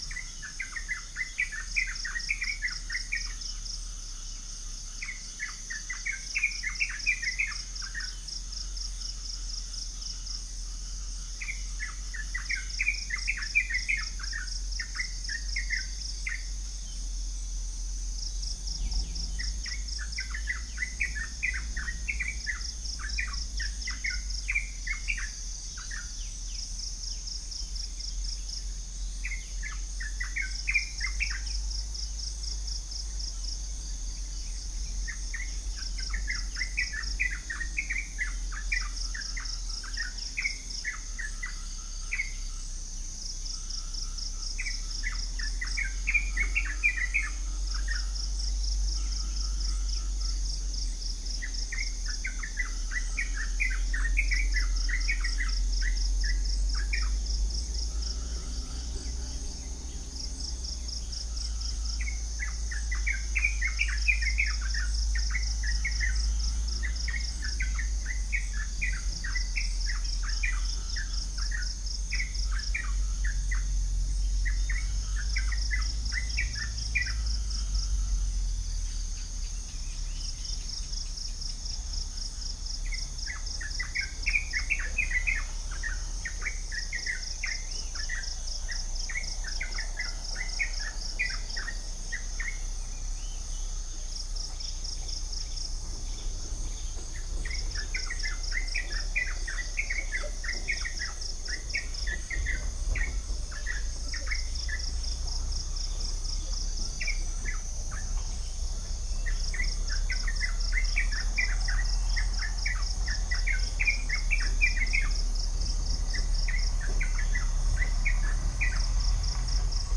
Upland plots dry season 2013
Orthotomus sericeus
Pycnonotus goiavier
Copsychus saularis
Halcyon smyrnensis